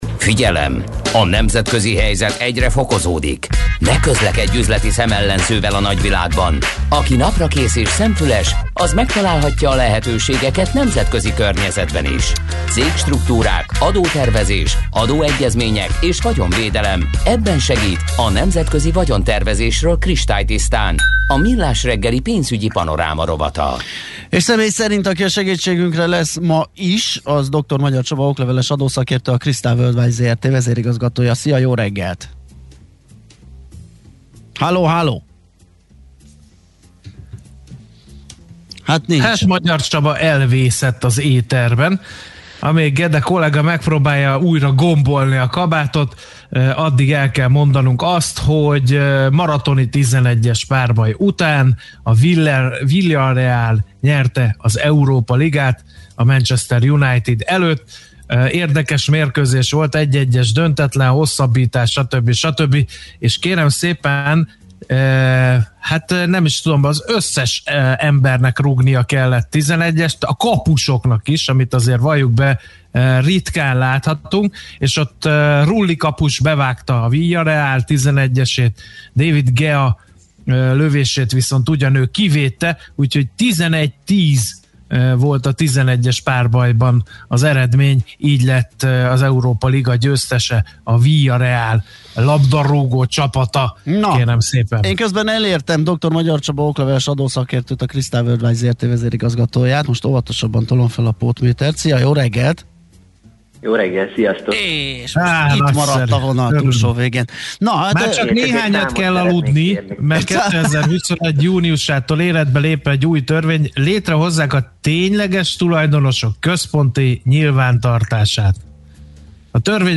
Műfaj: Blues.